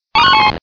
Sounds / Cries / 215.wav